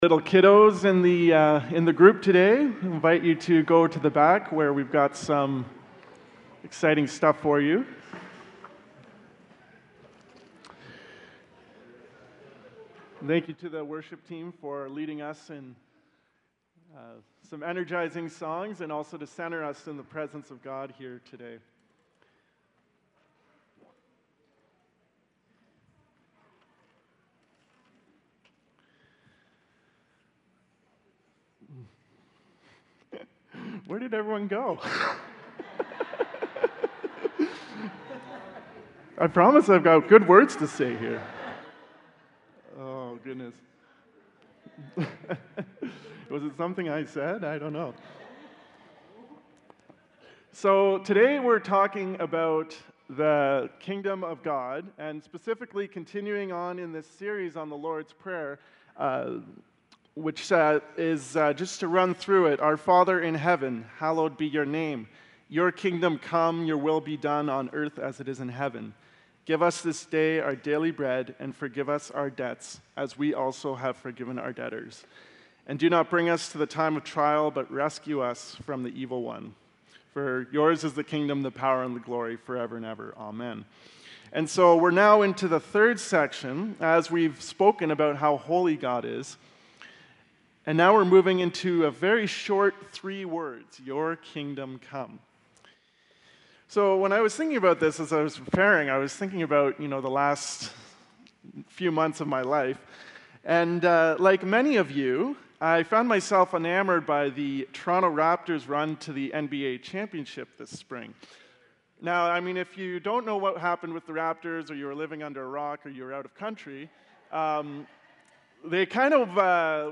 Sermons | The Gathering Church